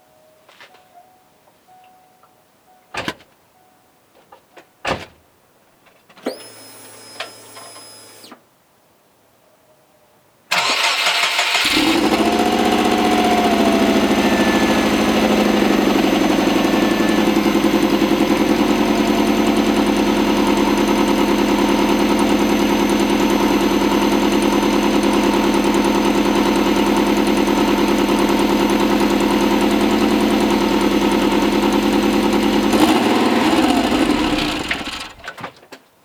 始動音もありますよ。
音は録音してみたんですけど、上手に取れませず。
乾いた音になってしまいます。
本当はもっと水中にいるみたいな音なのに、
ドデカい燃料ポンプの音と、重いセルの音がすこなんだ。